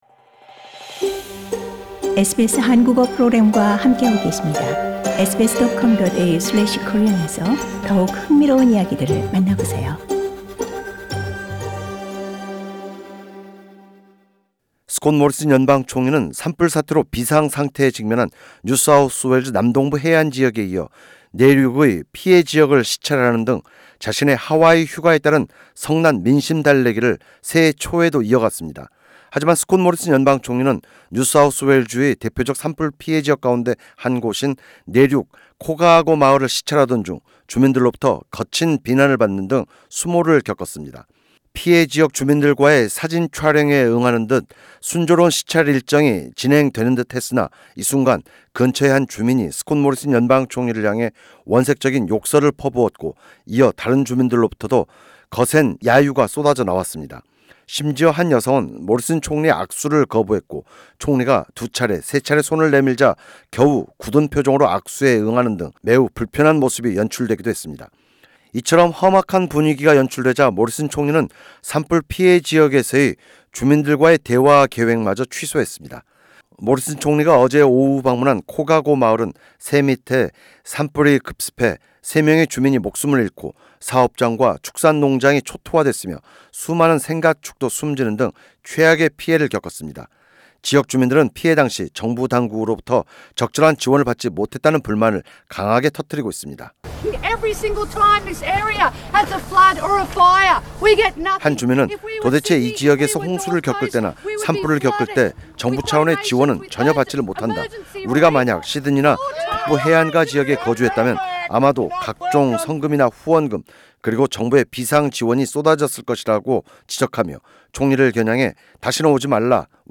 Prime Minister Scott Morrison is heckled as he tours a fire-ravaged community.